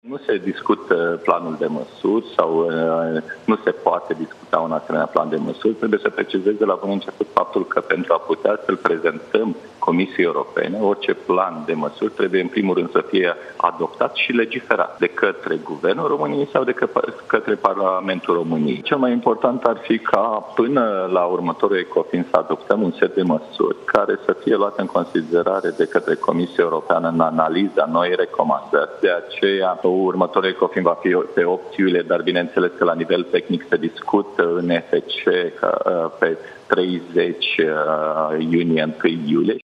Oficialul a explicat la Digi24 că România mai are puțin timp la dispoziție pentru a adopta ajustările bugetare, înainte de termenul limită de 30 iunie: